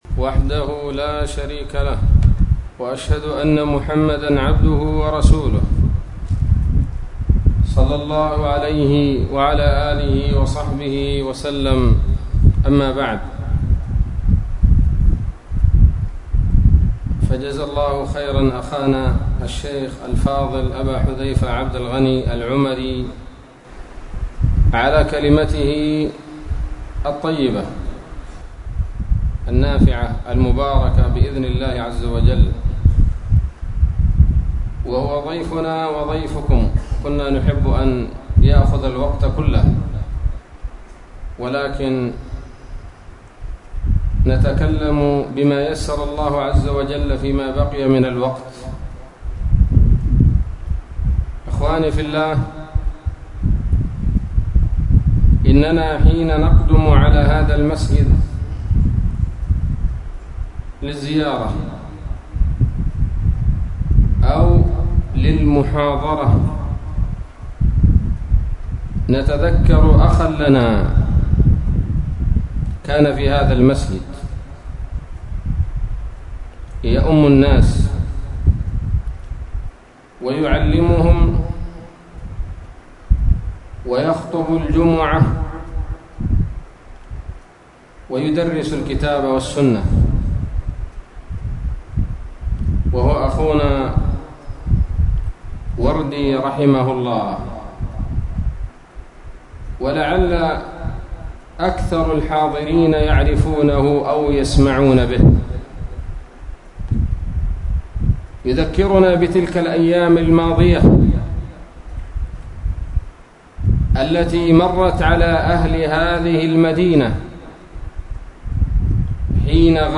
محاضرة قيمة بعنوان
ليلة السبت 18 صفر 1443هـ، بمسجد الإستقامة - خور مكسر - عدن